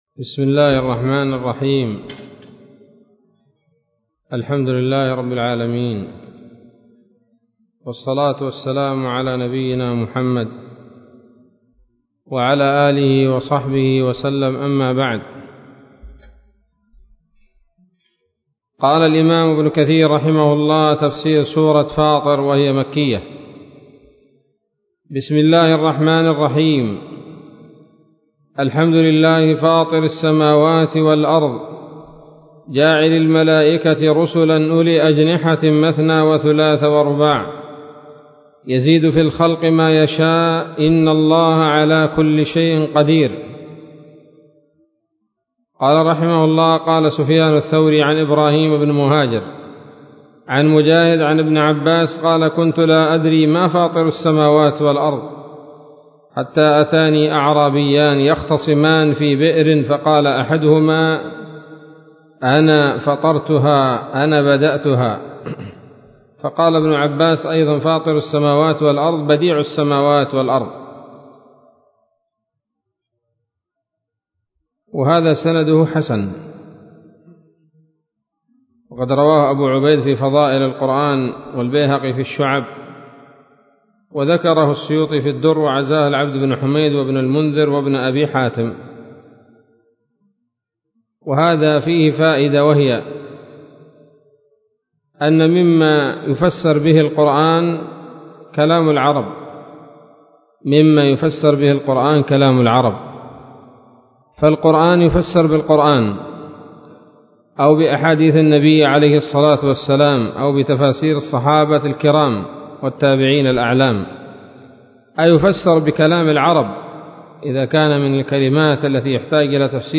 الدرس الأول من سورة فاطر من تفسير ابن كثير رحمه الله تعالى